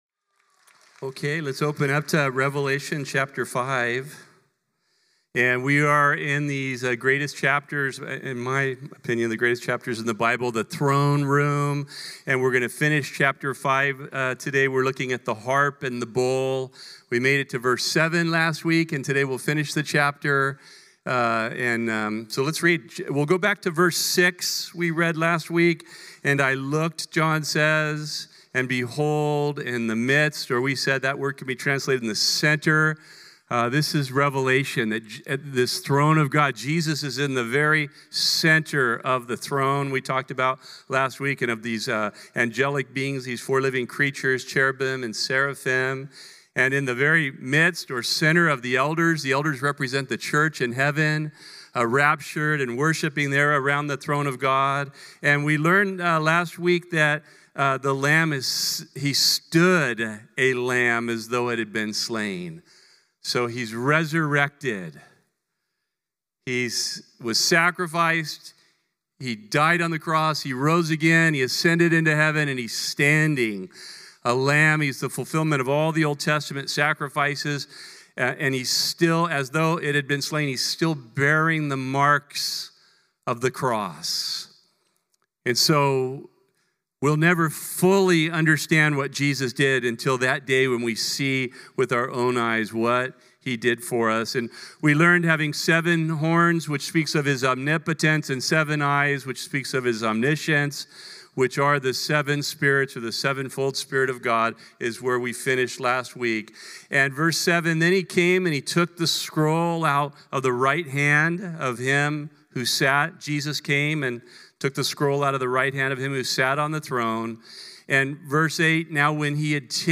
The Bible study given at Calvary Chapel Corvallis on Sunday, December 21, 2025..